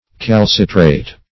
Meaning of calcitrate. calcitrate synonyms, pronunciation, spelling and more from Free Dictionary.
Search Result for " calcitrate" : The Collaborative International Dictionary of English v.0.48: Calcitrate \Cal"ci*trate\, v. t. & i. [L. calcitratus, p. p. of calcitrare.